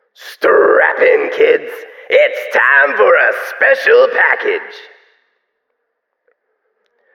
🌲 / midnight_guns mguns mgpak0.pk3dir sound announcer